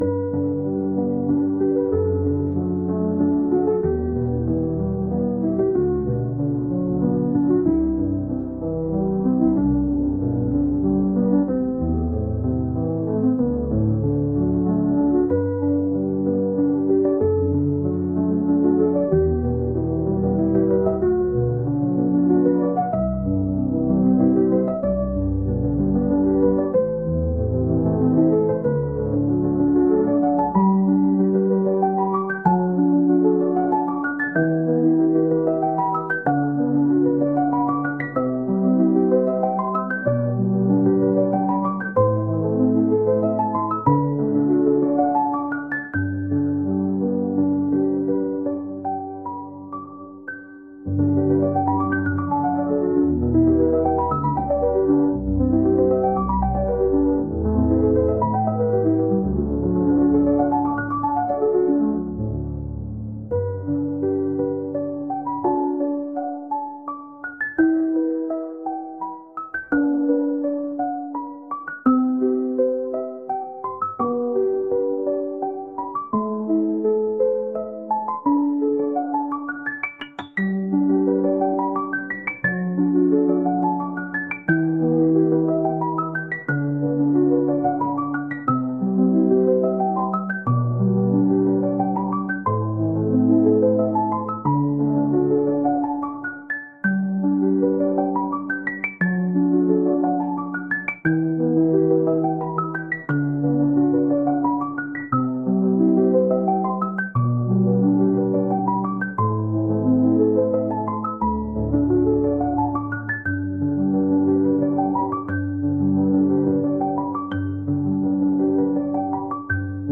滝の音
滝の音.mp3